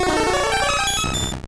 Cri de Mewtwo dans Pokémon Or et Argent.